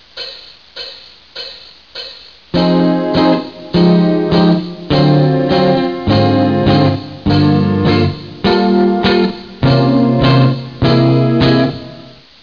Когда я почувствовал, что холодно звучащие аккорды вдохновляют меня к лучшей игре, первое, что я сделал, - это разбавил основную прогрессию I-VI-II-V промежуточными тонами, такими, как девятая и одиннадцатая ступень, и альтерациями, такими, как повышенная девятая и повышенная пятая ступень.
Заметьте, что хоть основная гармония та же самая, звуки в ПРИМЕРЕ 2 богаче и более интересны, чем основные звуки в ПРИМЕРЕ 1.